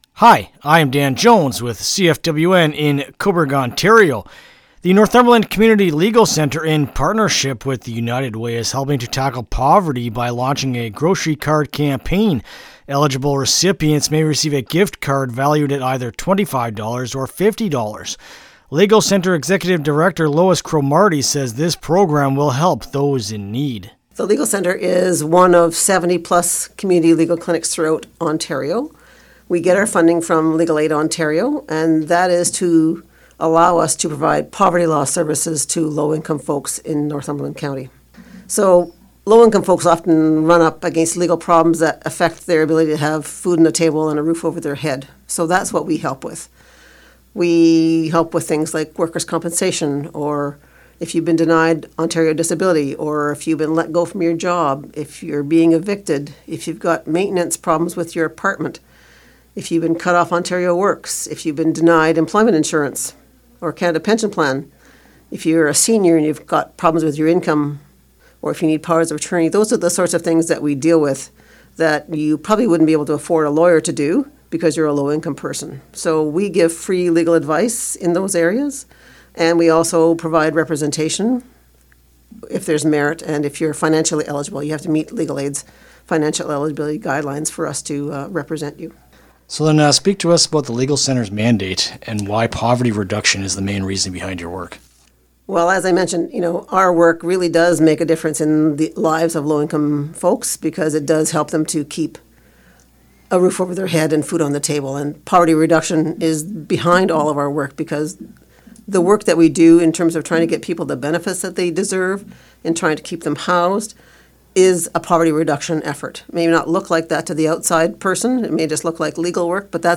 Legal-Centre-Interview-LJI.mp3